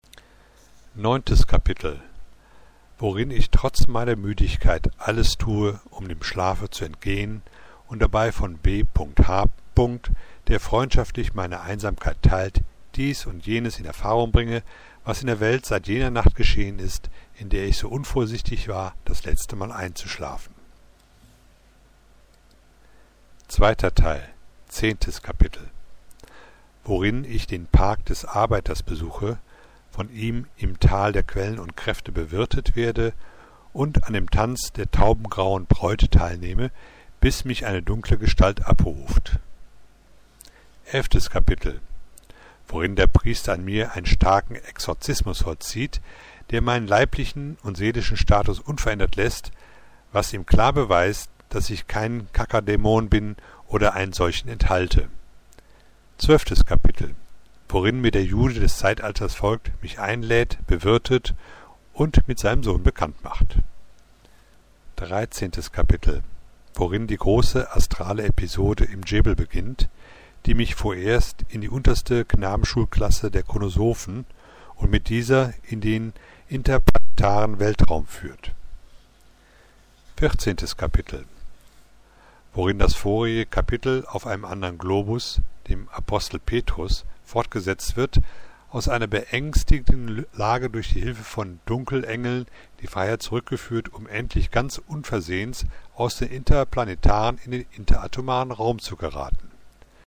Hörbuch (mp3 Format): Stern der Ungeborenen * Kapitel 1-8 (1,9 MB) *